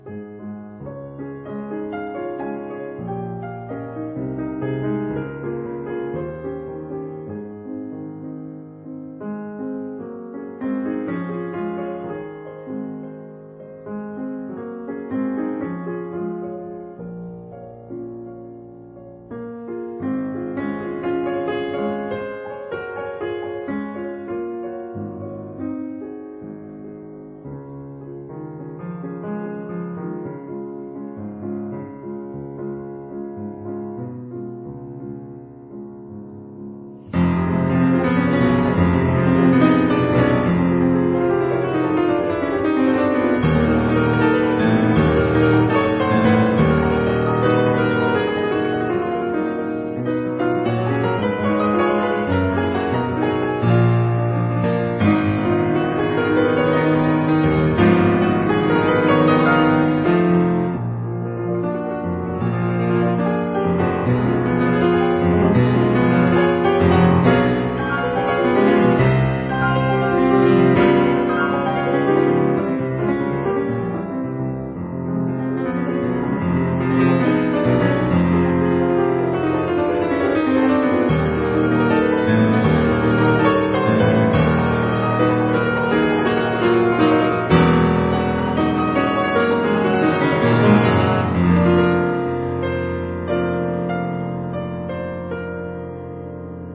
這是個充滿多變的時代：後期浪漫、新古典、現代主義、印象派、爵士⋯⋯